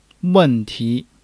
wen4-ti2.mp3